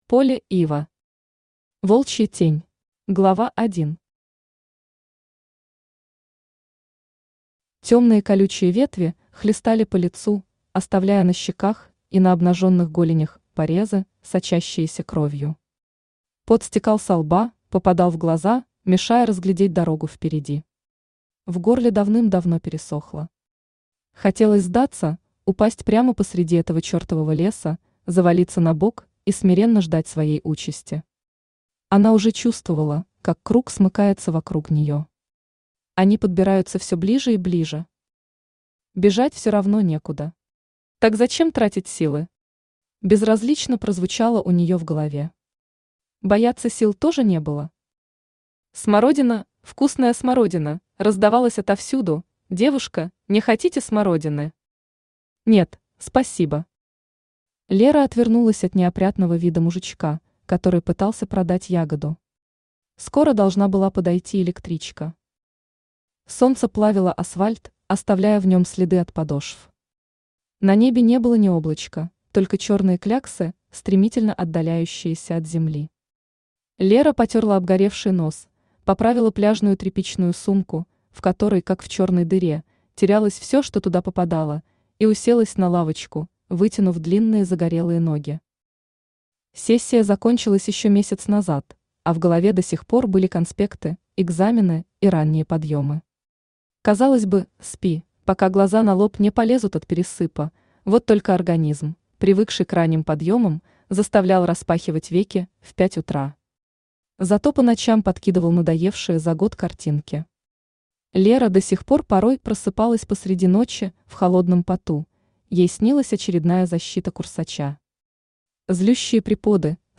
Aудиокнига Волчья тень Автор Полли Ива Читает аудиокнигу Авточтец ЛитРес. Прослушать и бесплатно скачать фрагмент аудиокниги